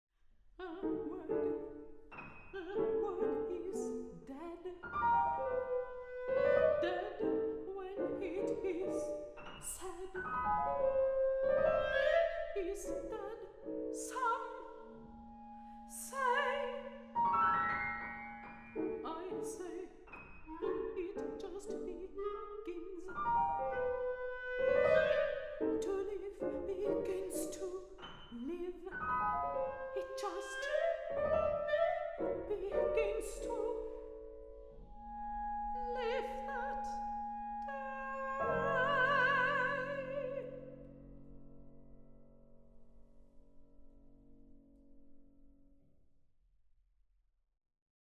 Soprano
Clarinet
Piano
Recording: Tonstudio Ölbergkirche, Berlin, 2023